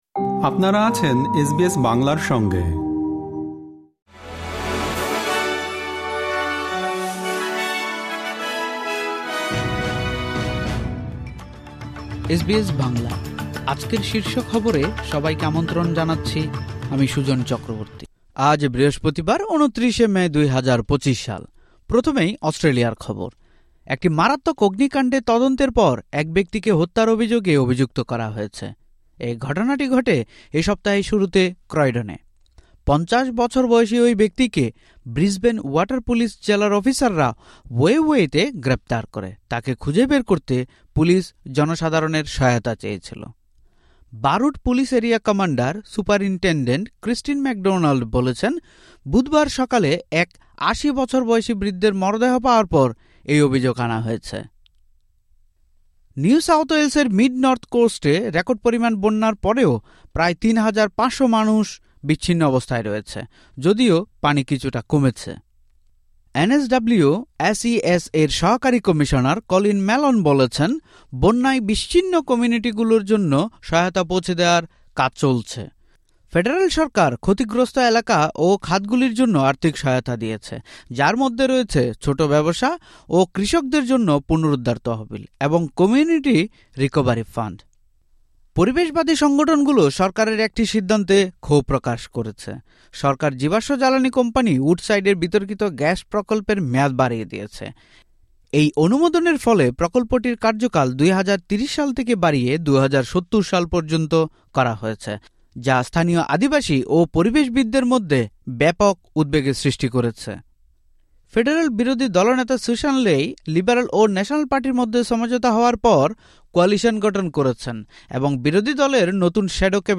এসবিএস বাংলা শীর্ষ খবর: ২৯ মে, ২০২৫